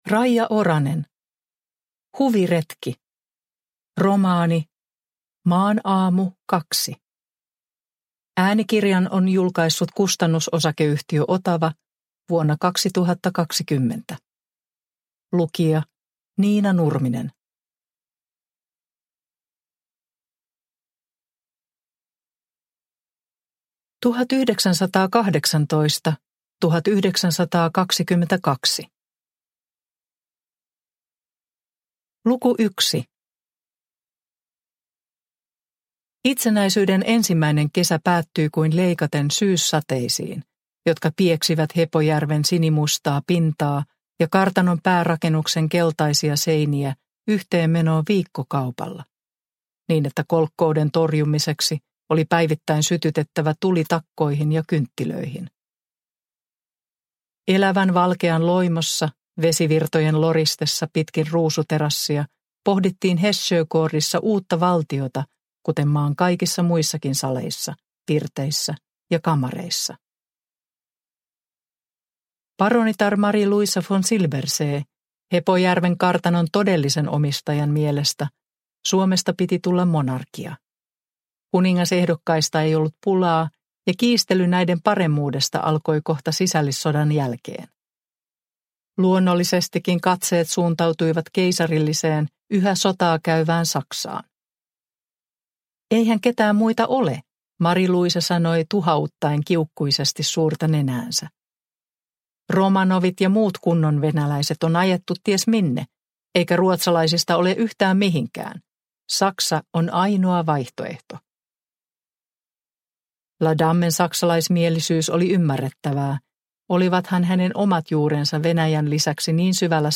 Huviretki – Ljudbok – Laddas ner